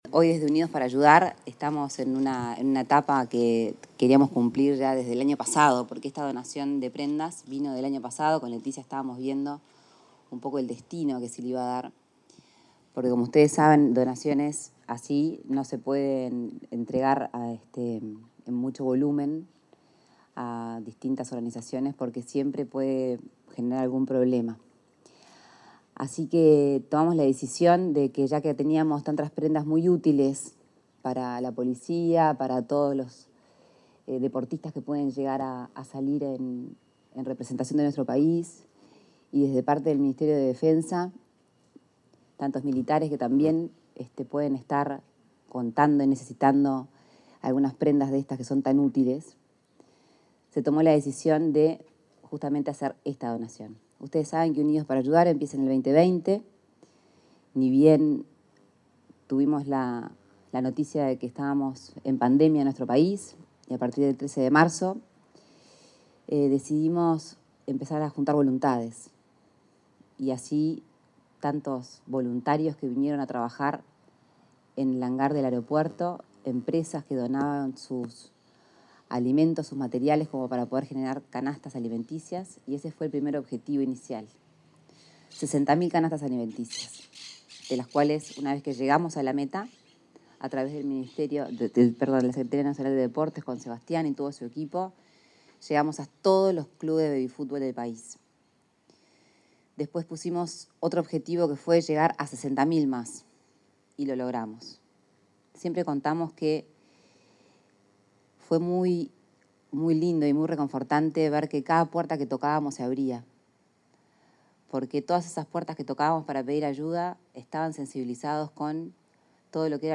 Declaraciones a la prensa de Lorena Ponce de León